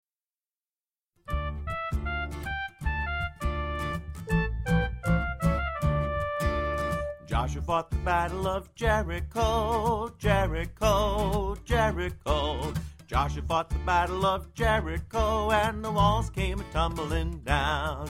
Folk Song Lyrics and Sound Clip